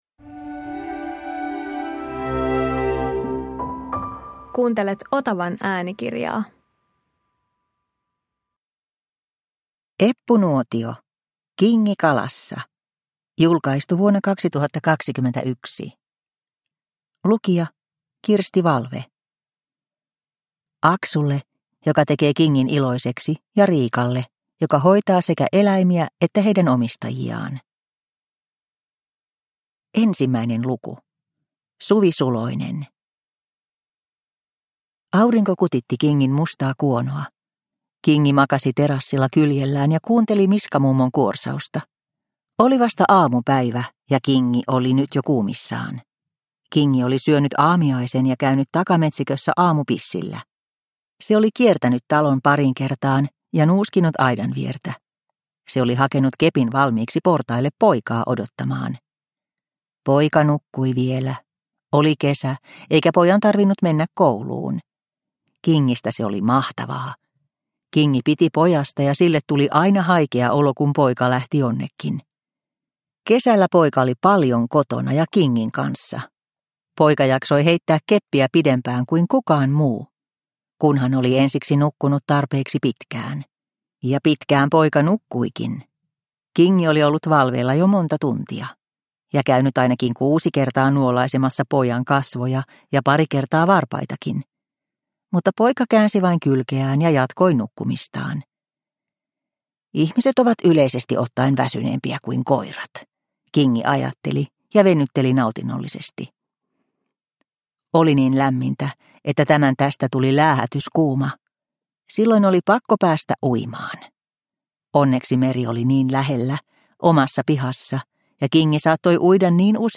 Kingi kalassa – Ljudbok – Laddas ner